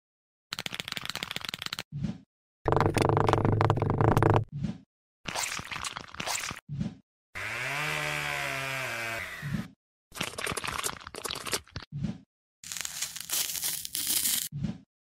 Cristiano Ronaldo as ASMR Food sound effects free download